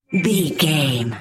Sound Effects
Atonal
magical
mystical